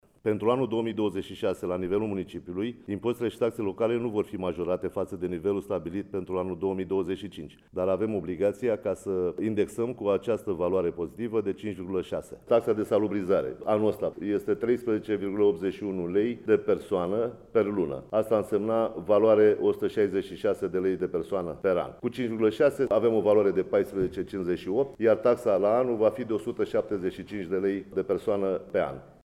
Dezbatere publică.
Anunțul a fost făcut într-o dezbatere publică organizată la Casa de Cultură Ioan N. Roman din Medgidia.